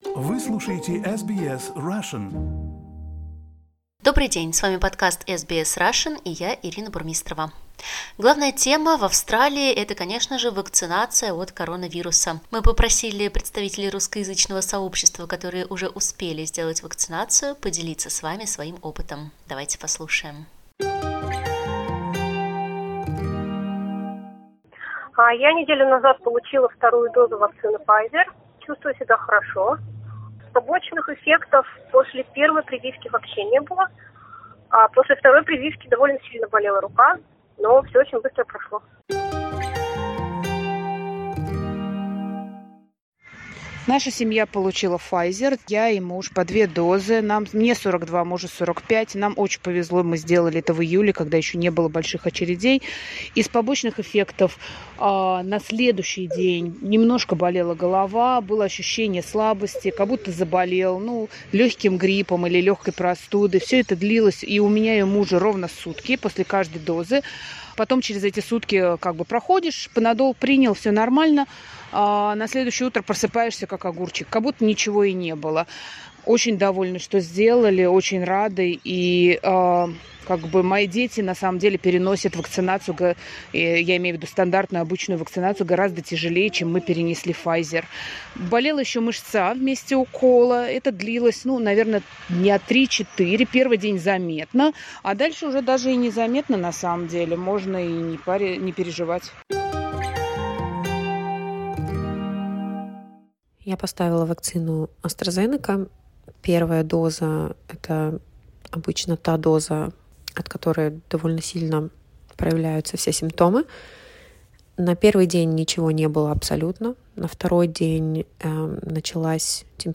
How did you feel after your Covid-19 vaccination? Vox-pop
We asked people from the Russian community in Australia to share their experience of COVID-19 vaccination.